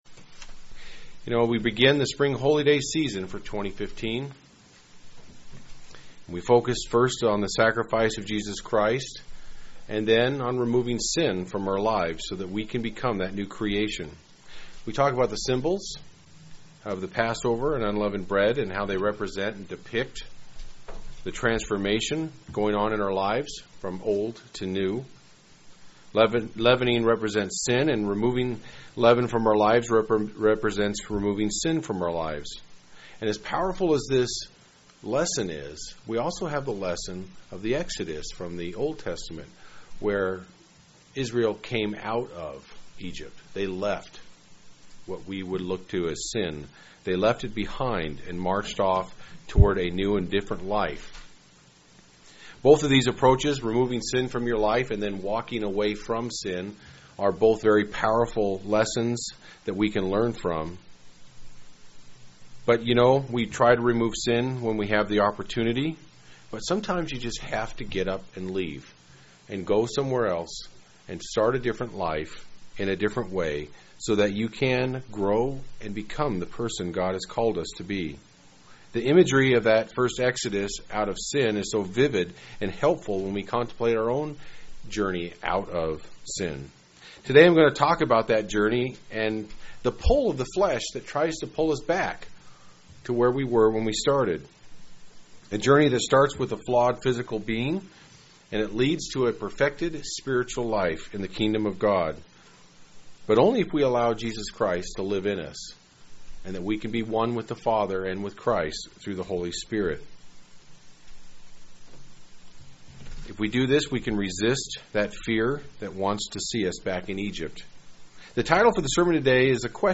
UCG Sermon Notes As we begin the Spring Holy Day season for 2015, we focus first on the sacrifice of Jesus Christ and next on removing sin from our lives.